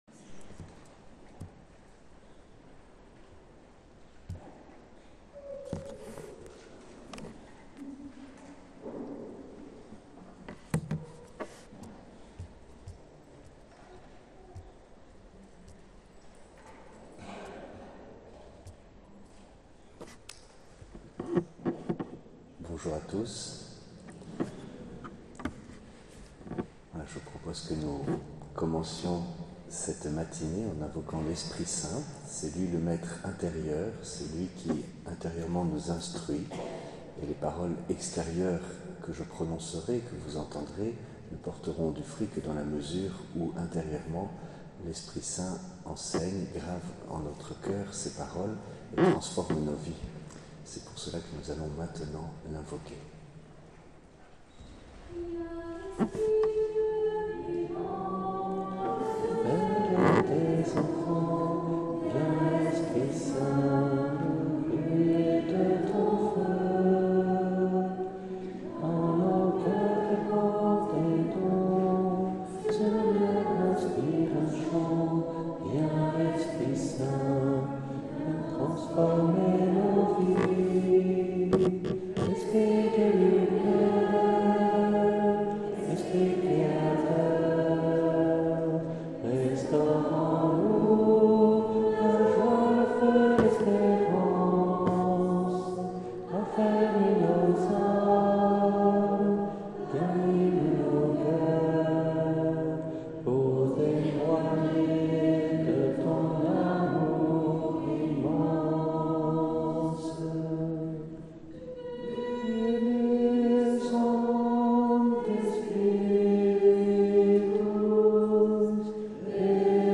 Conf.